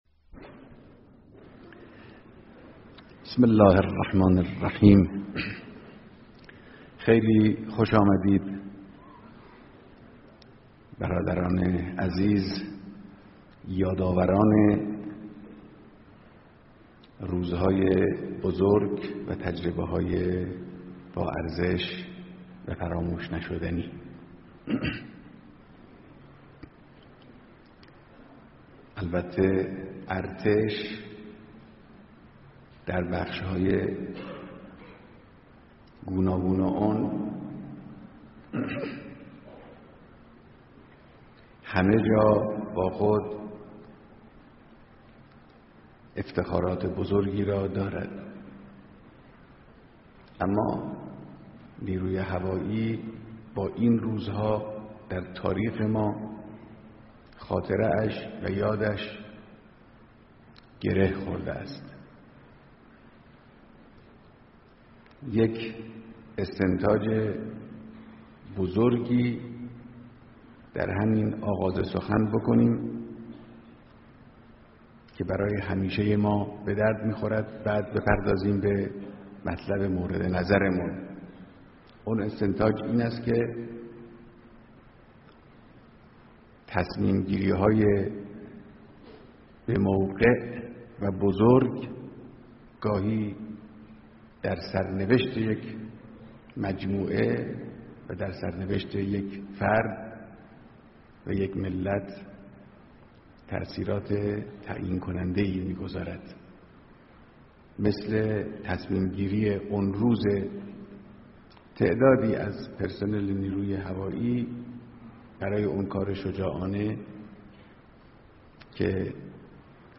صوت کامل بیانات
بيانات در ديدار فرماندهان و جمعی از پرسنل نیروى هوایى ارتش